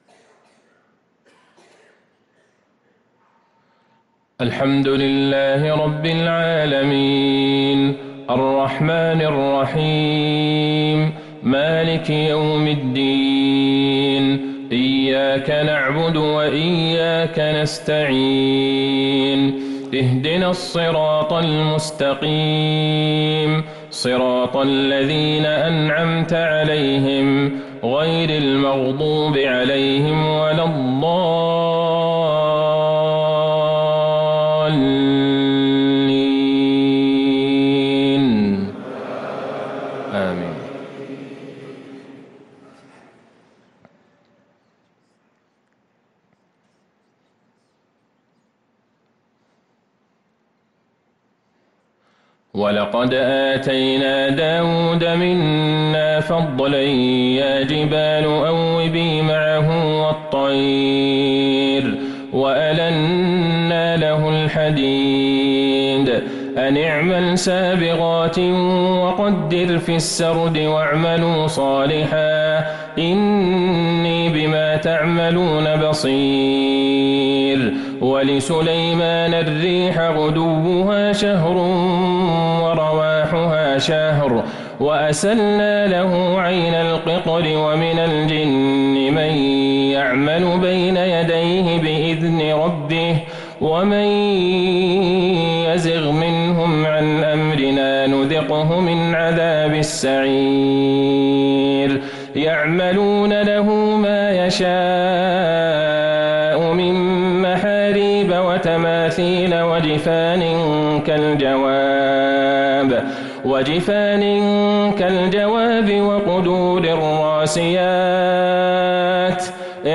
صلاة العشاء للقارئ عبدالله البعيجان 28 ربيع الآخر 1445 هـ
تِلَاوَات الْحَرَمَيْن .